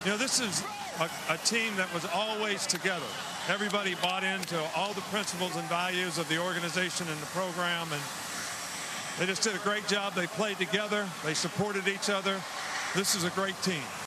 Head coach Nick Saban spoke on his team: